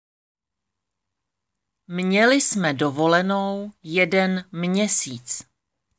Děkuji za zájem o nahrávku výslovnost MĚ - Měli jsme dovolenou jeden měsíc
Tady si můžete stáhnout audio na výslovnost MĚ: Měli jsme dovolenou jeden měsíc.